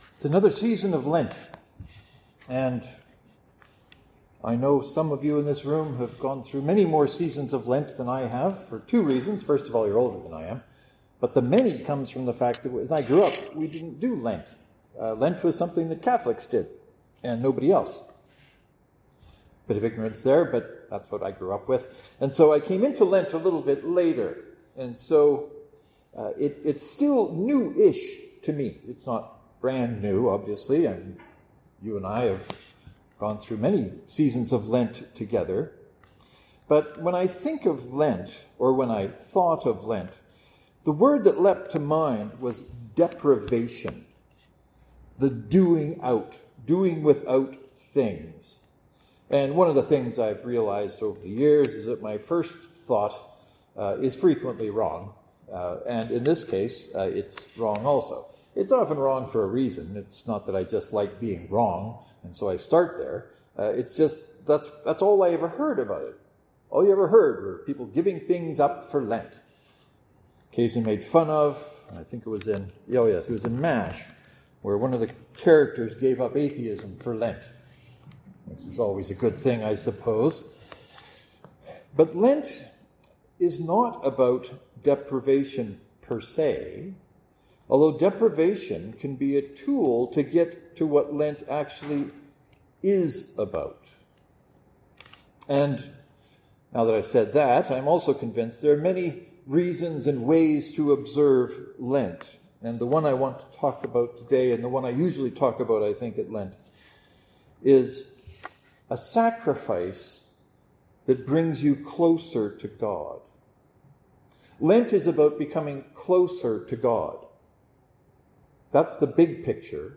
I am hardly ever the best person to determine whether or not I accomplished what I was hoping to in a sermon so I’ll leave that to you.